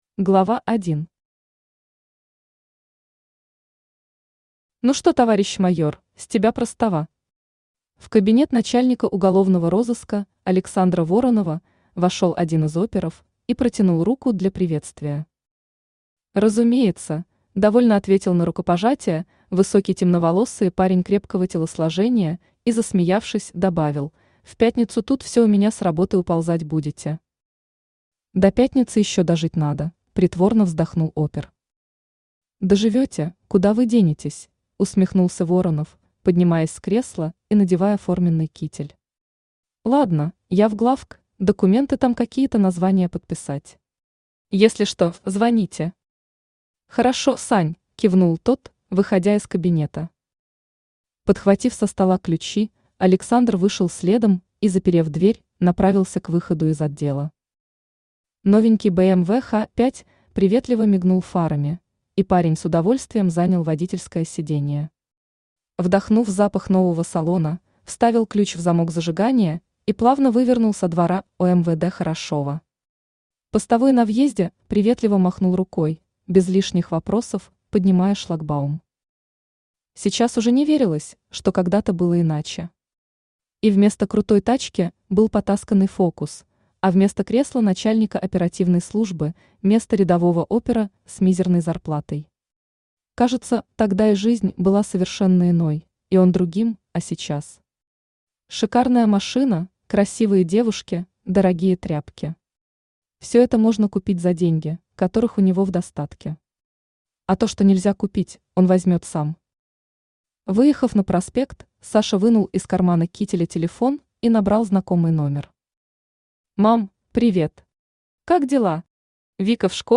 Аудиокнига Тебе меня не сломить | Библиотека аудиокниг
Aудиокнига Тебе меня не сломить Автор Александра Ронис Читает аудиокнигу Авточтец ЛитРес.